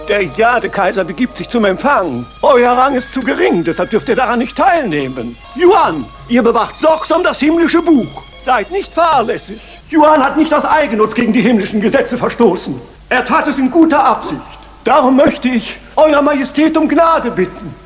Als ich davon las, recherchierte ich ein wenig und war hellauf begeistert, als ich auf Youtube eine alte Fernseh-Aufzeichnung fand, die dort jemand vor 3 Jahren online stellte.